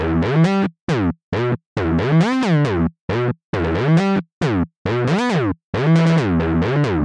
新酸味波塔姆特低音线E小调136
描述：一个有趣的中音波段的低音线。我认为它有一种真正的酸的感觉。在电子或Breakbeat中可能也会很酷。这是在E小调。
Tag: 136 bpm Acid Loops Bass Synth Loops 1.19 MB wav Key : E